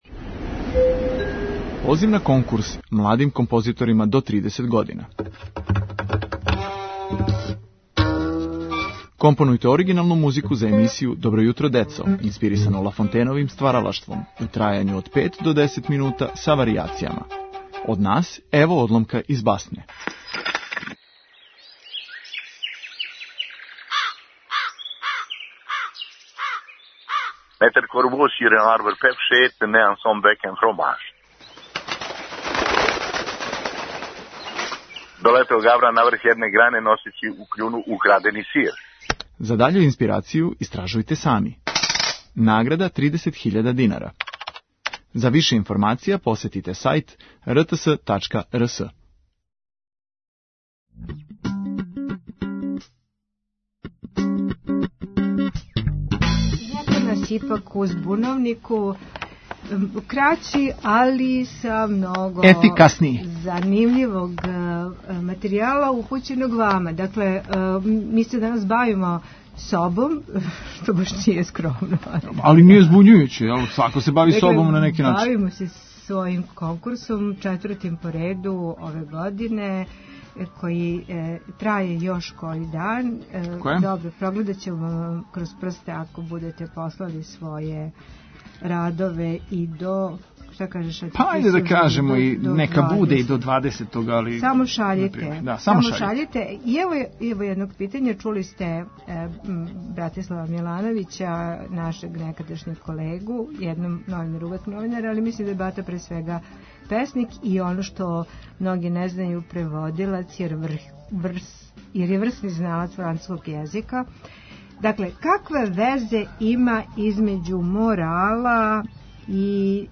О усаглашавању и прилагођавању говоре нам деца, ИТ стручњаци, ватерполисти...